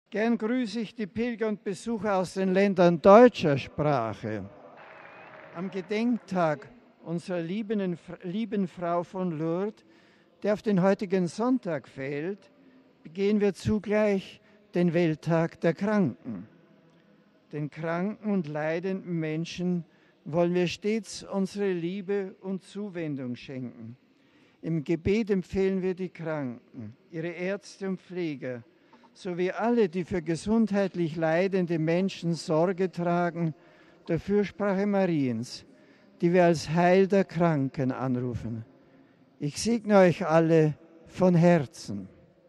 MP3 Papst Benedikt XVI. ist für eine stärkere Forschung im Bereich der Schmerz-Therapie. Das sagte er heute beim Angelusgebet auf dem Petersplatz in Rom.